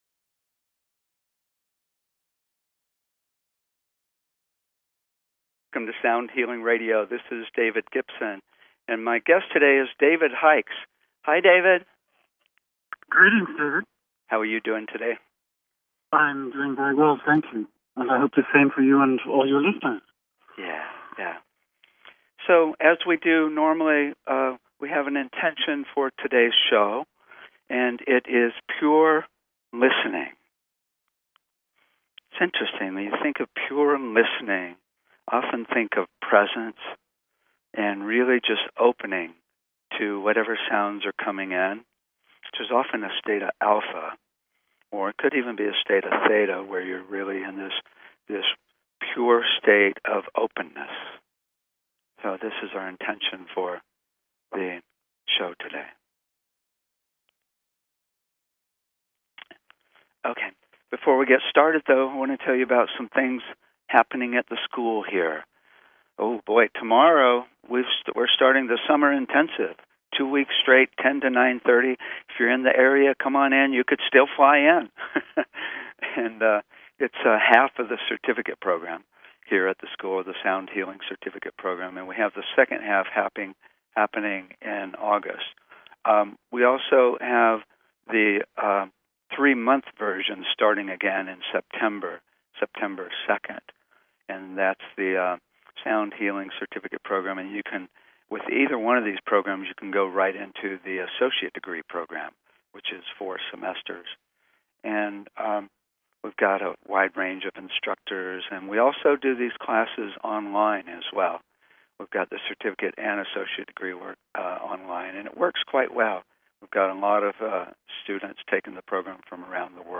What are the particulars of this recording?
Songs include: Halleluya, Rainbow Voice and Special Time Three